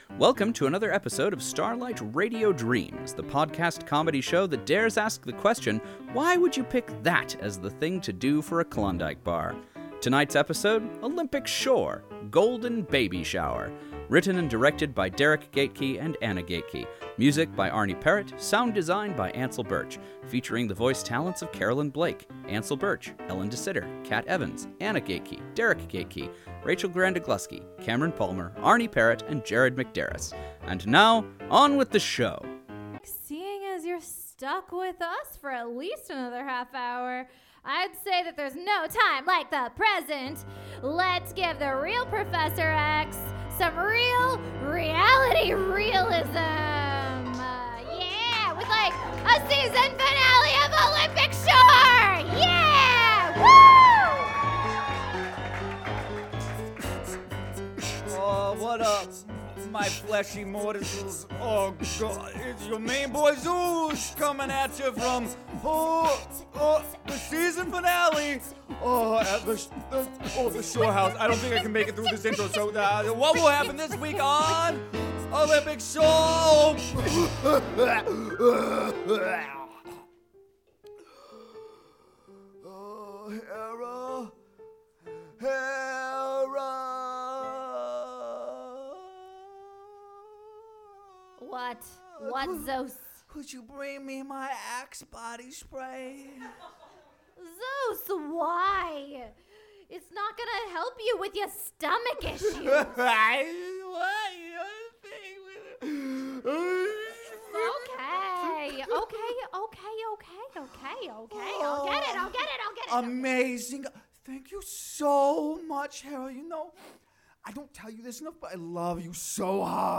I got to guest star as a creepy Pan (with faint undertones of the Midnight Bomber What Bombs at Midnight), as well as a TV hero, last month with Starlight Radio Dreams.